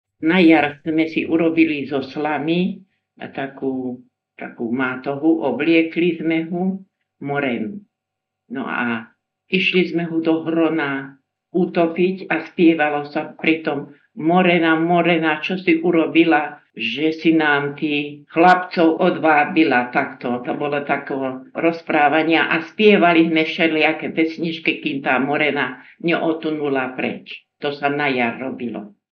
Vynášanie Moreny v Nemeckej 001-03